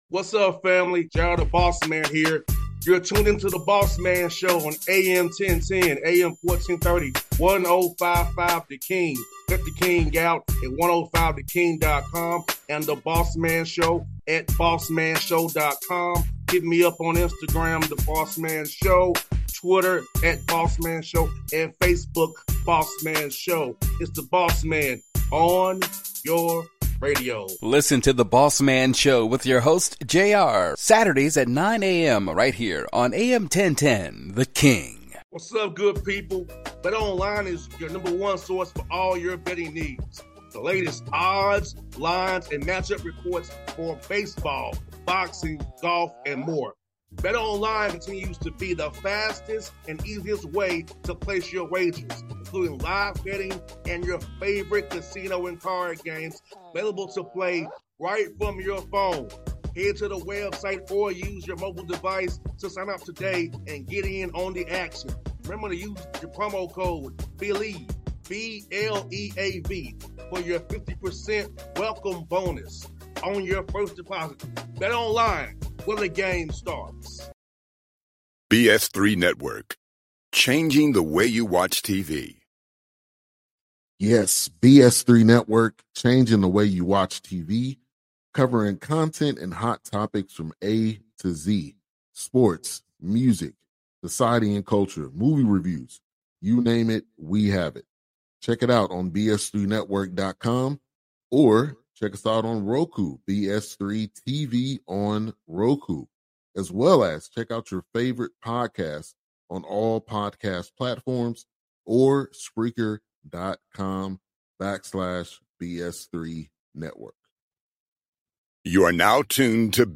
Will Wade Interview